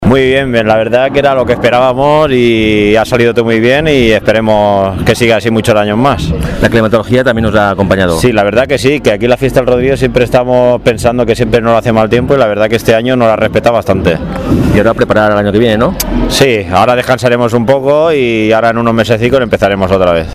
Alberto Navarro, pedáneo del Rodriguillo se mostraba satisfecho por el desarrollo de los festejos